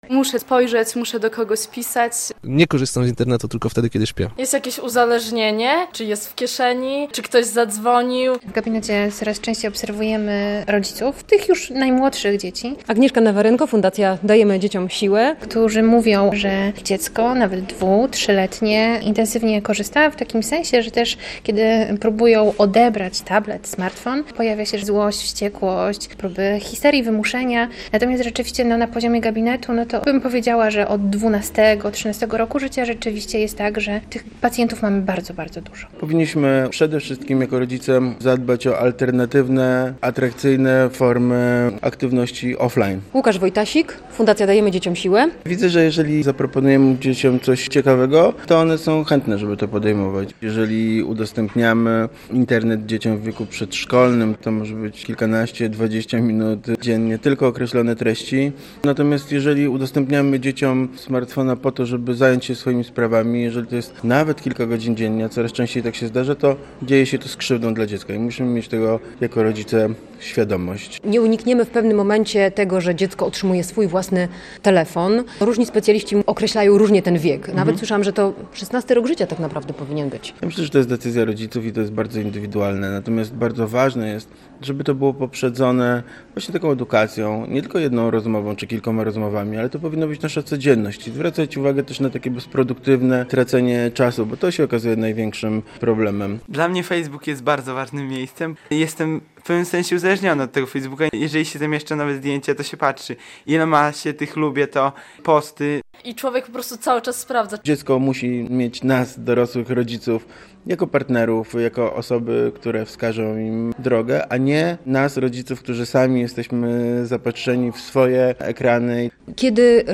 Jak uczyć dzieci korzystania z Internetu? - relacja